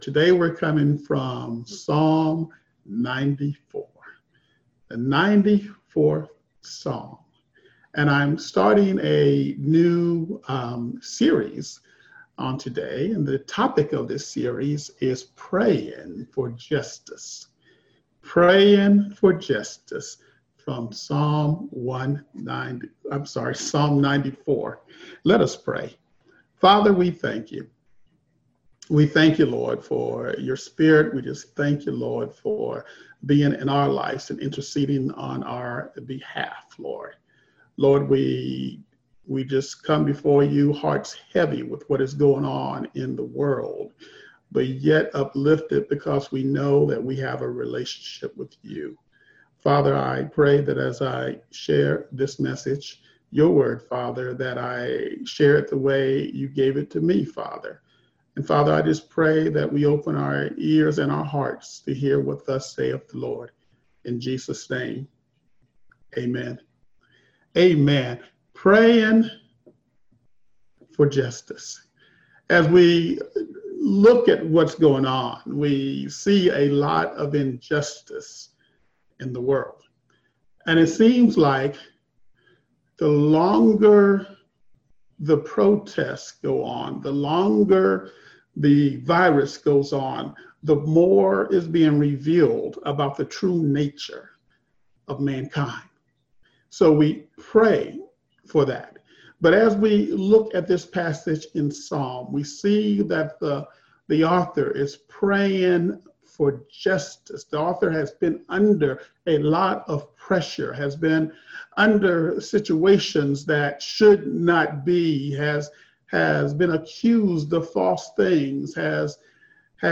Enjoy Beacon Light's Virtual Service with a message from Psalm 94:1-7